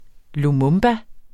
Udtale [ loˈmɔmba ]